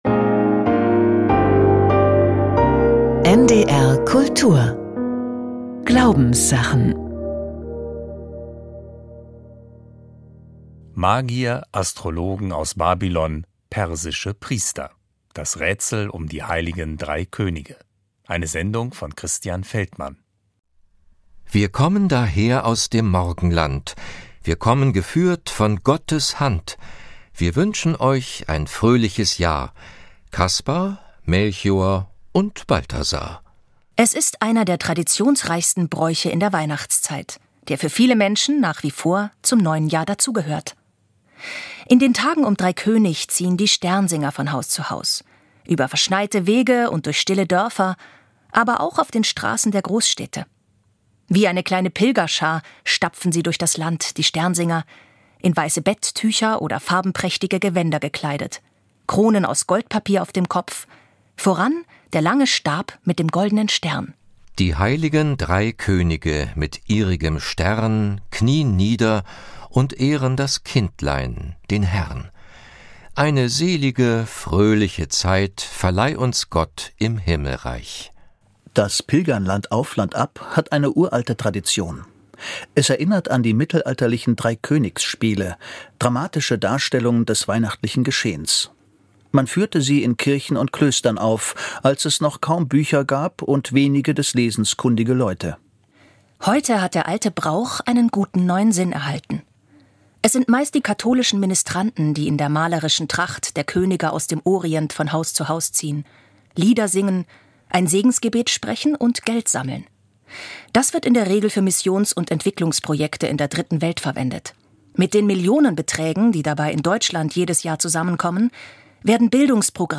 Kinderhörspiel: Plumes wundersame Reise zum Horizont - 05.01.2025